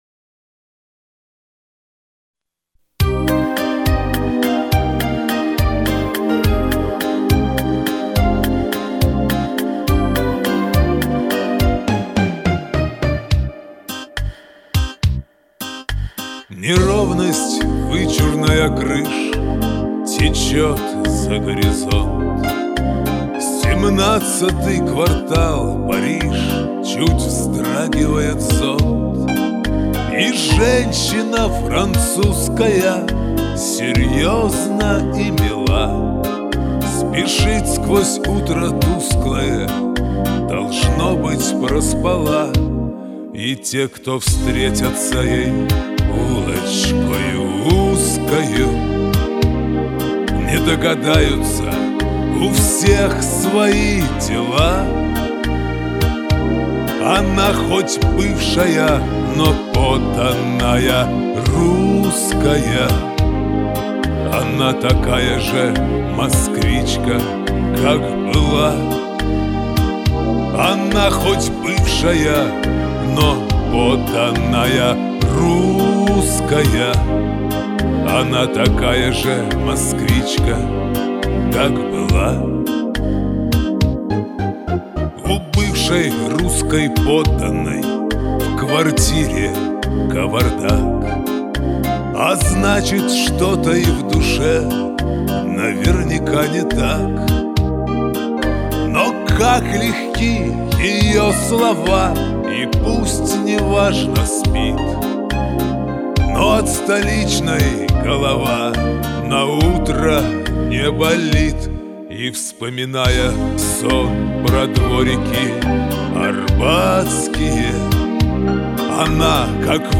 рубленно пою и без достаточного лиризма